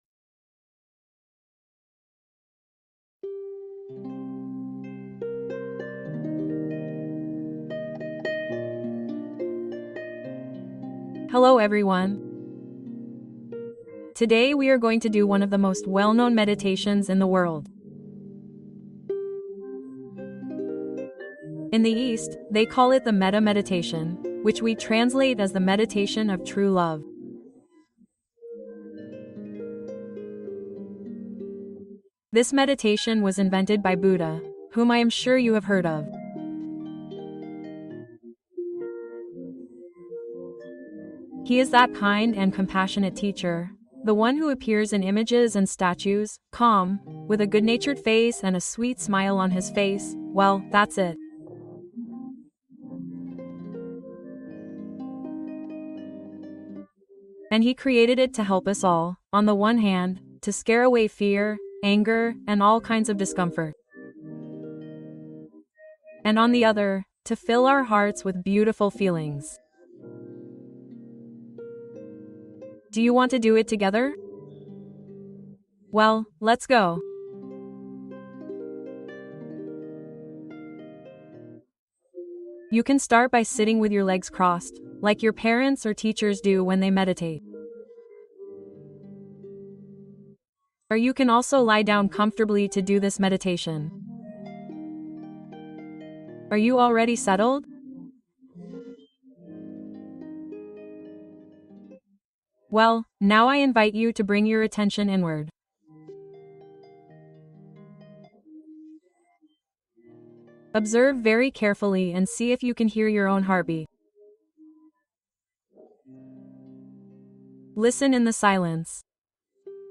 Meditación para niños: el amor universal como camino de paz